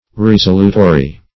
Resolutory \Res"o*lu*to*ry\ (r?z"?-l?-t?-r?)